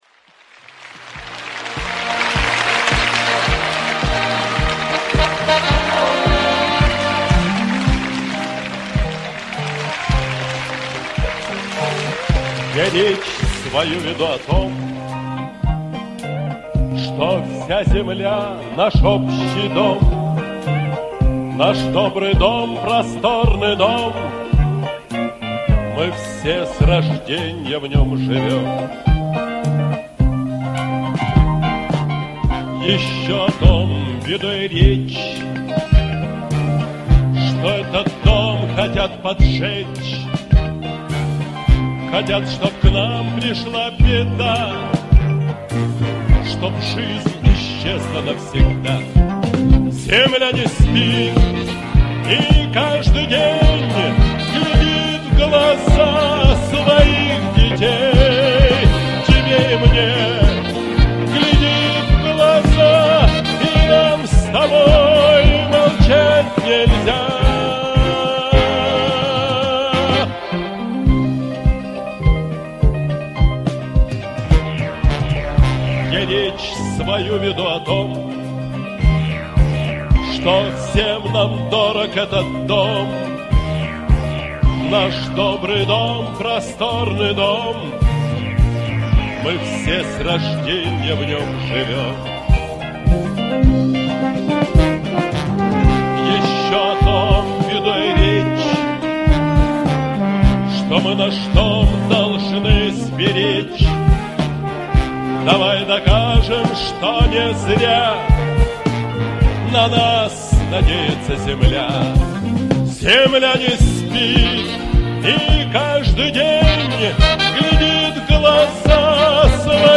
Вырезал из концерта